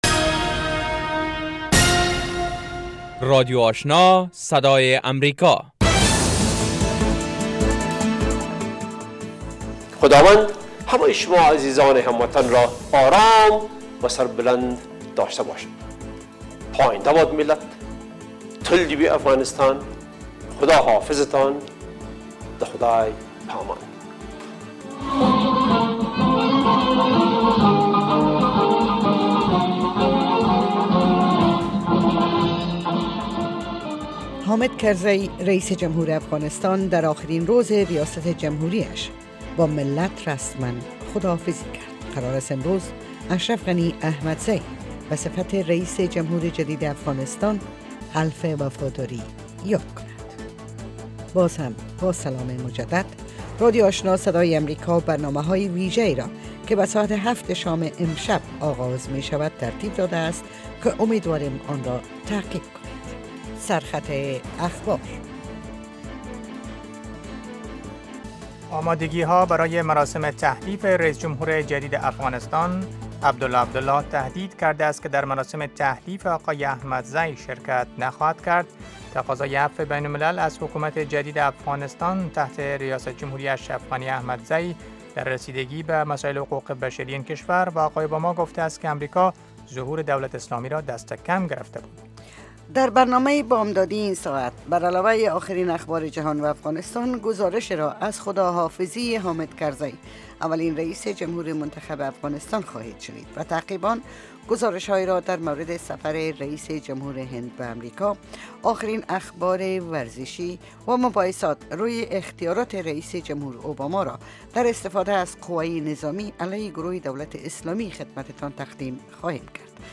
دومین برنامه خبری صبح
morning news show second part